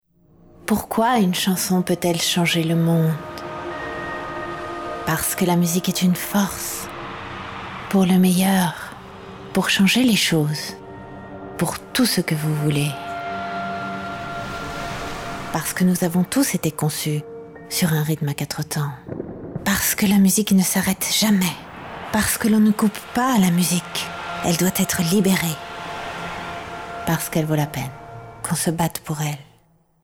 Voix jeune et dynamique, teinte sensuelle
Sprechprobe: Sonstiges (Muttersprache):